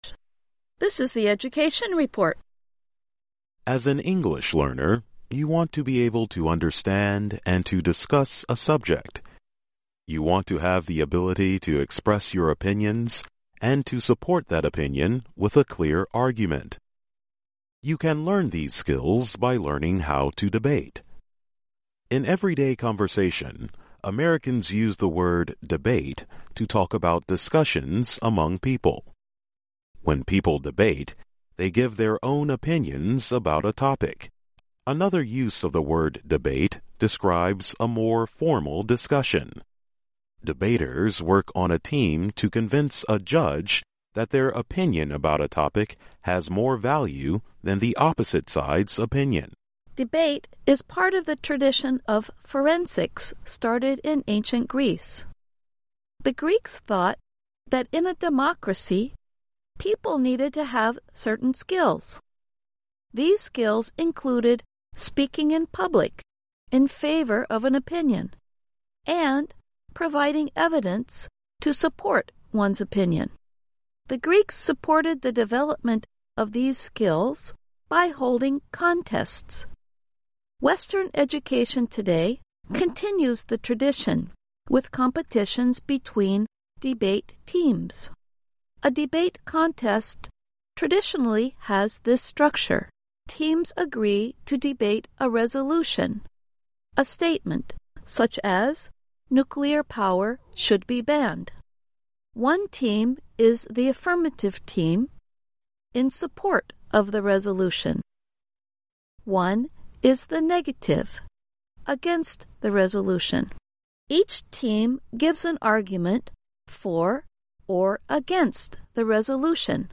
Learn English as you read and listen to a weekly show about education, including study in the U.S. Our stories are written at the intermediate and upper-beginner level and are read one-third slower than regular VOA English.